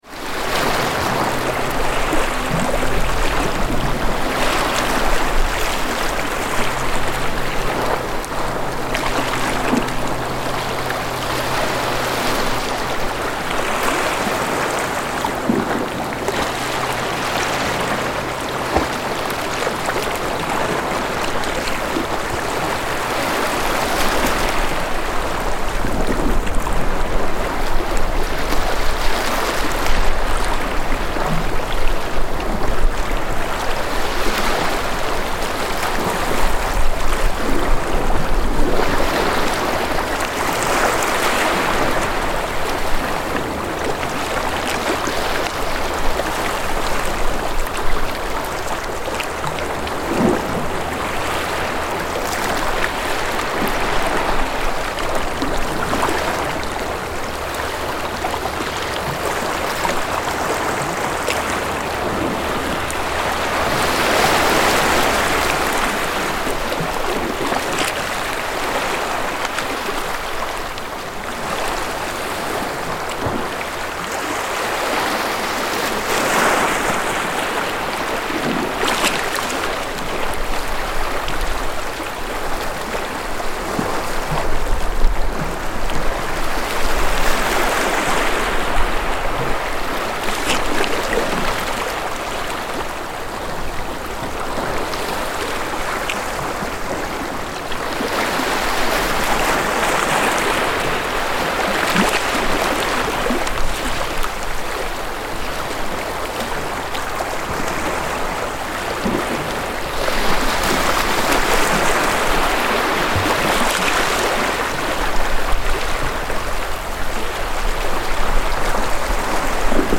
Aegean waves